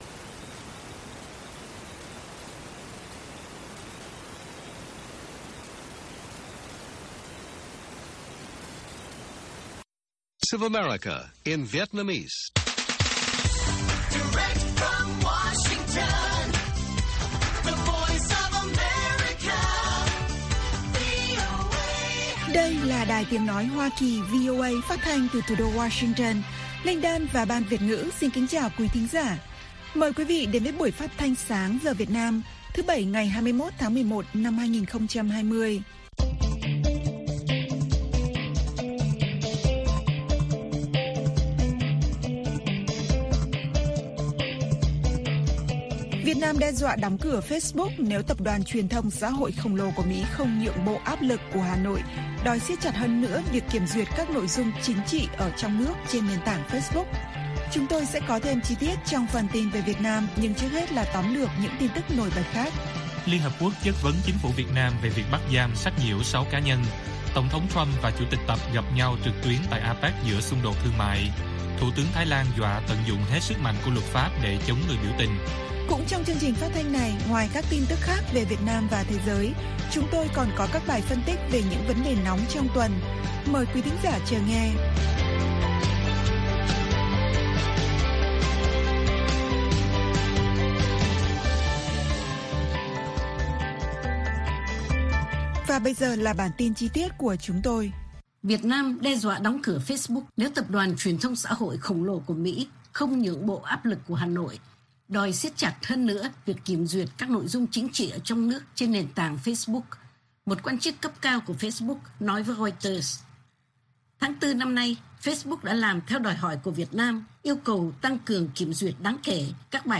Bản tin VOA ngày 21/11/2020